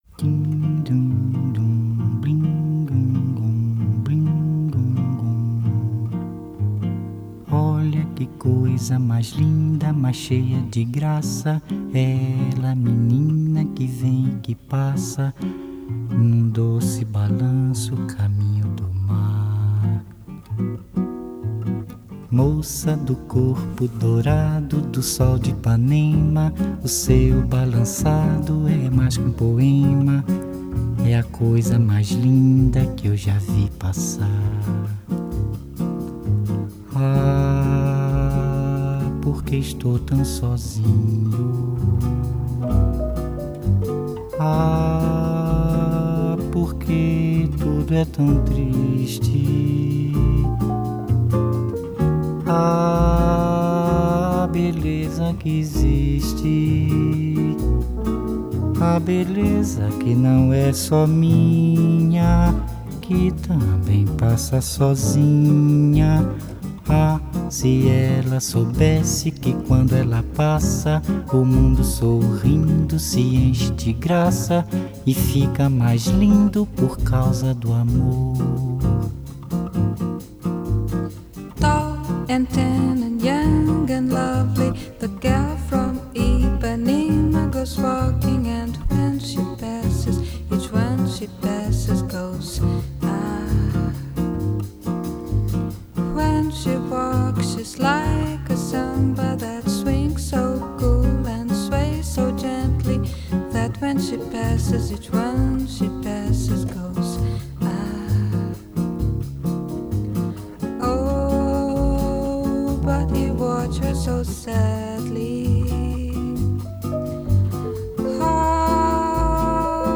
Genre: Bossa Nova